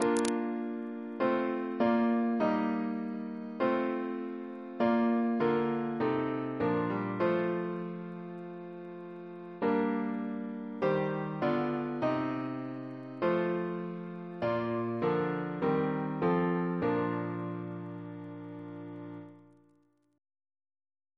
Double chant in B♭ Composer: Sir John Goss (1800-1880), Composer to the Chapel Royal, Organist of St. Paul's Cathedral Reference psalters: ACB: 253; CWP: 158; OCB: 14; PP/SNCB: 215; RSCM: 57